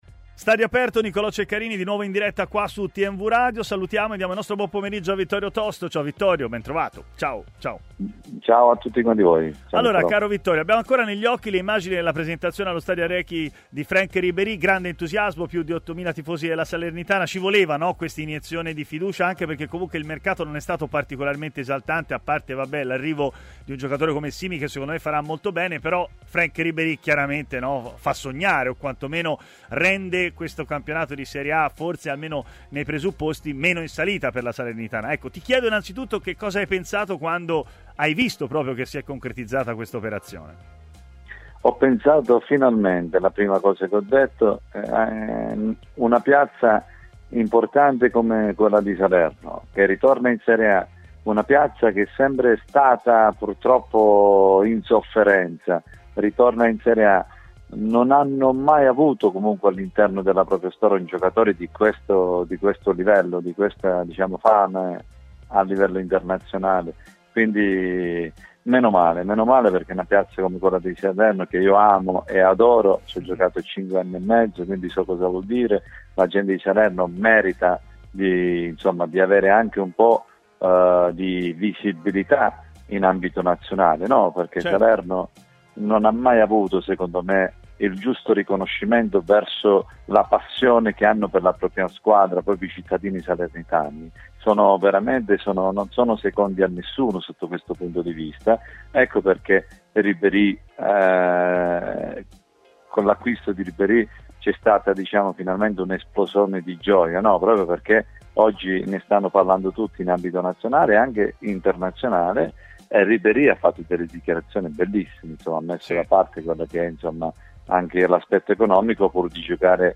ospite di Stadio Aperto, trasmissione di TMW Radio